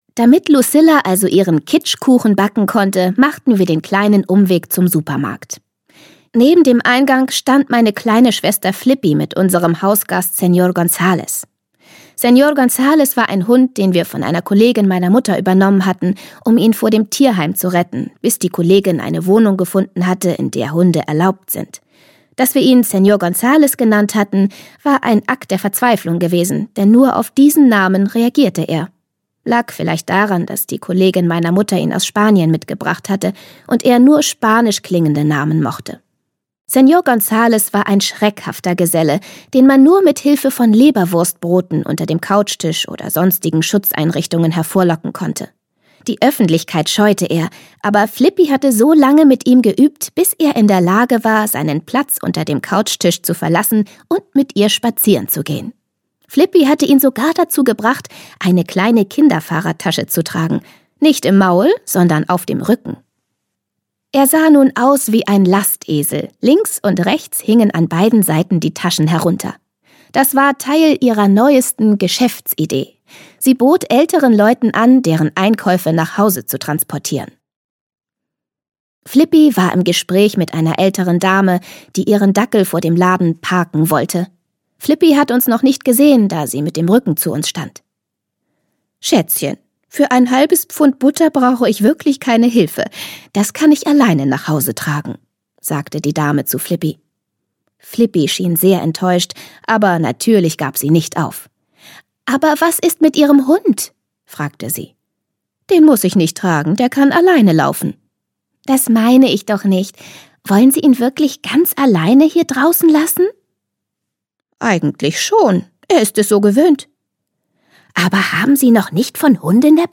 Freche Mädchen: Verflixt und fremdgeküsst - Hortense Ullrich - Hörbuch